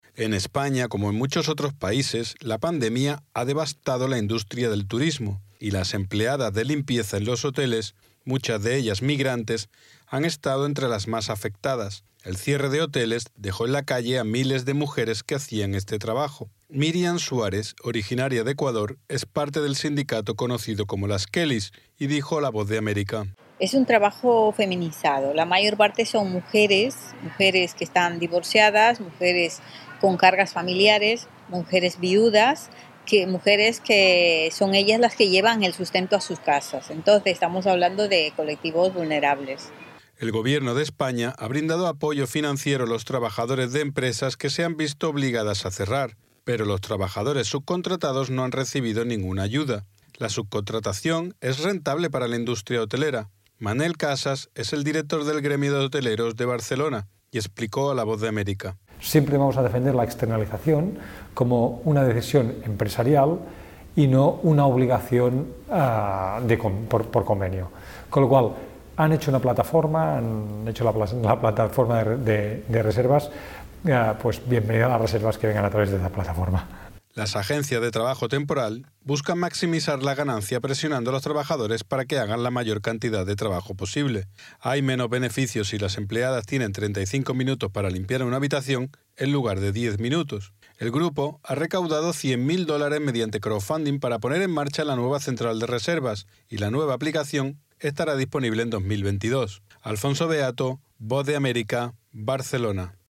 Un sindicato que representa a las limpiadoras de hotel en España, muchas de ellas migrantes, están preparando para lanzar una nueva aplicación de reservas que califica a los hoteles según cómo tratan a sus trabajadores. Desde España informa el corresponsal de la Voz de América